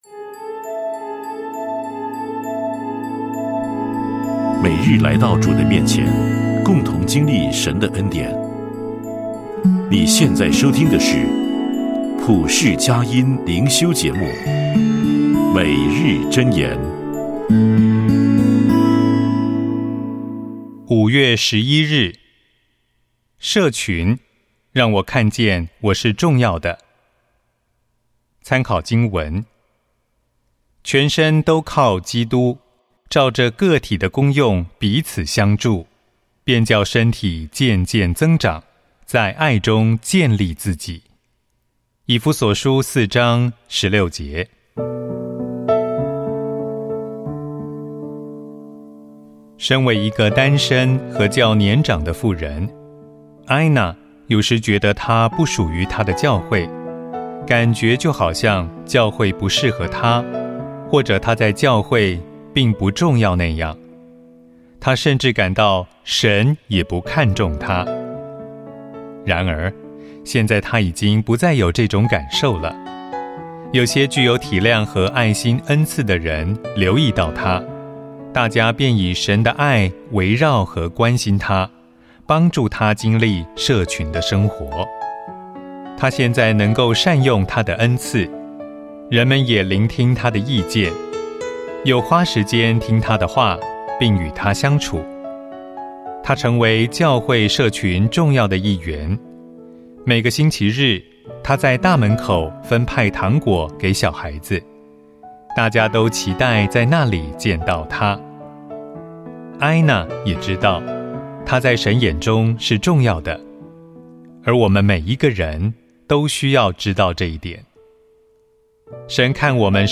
诵读